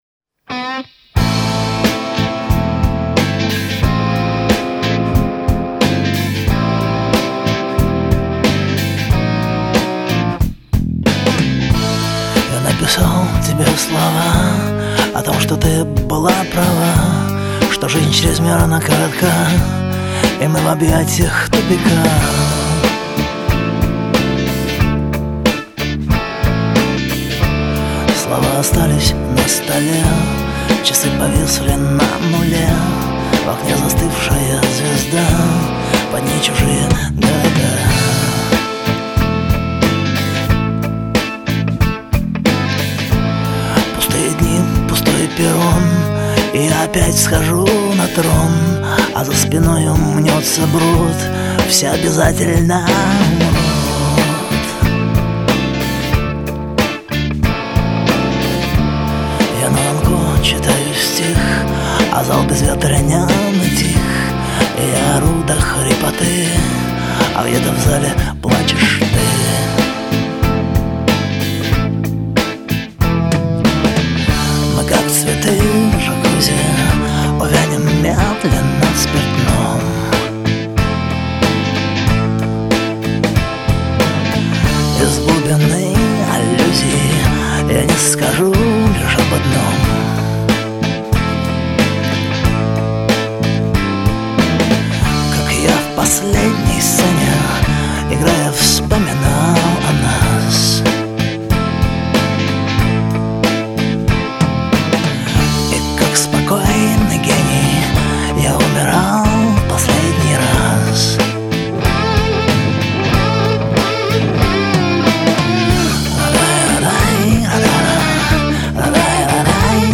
Поп рок
Софт рок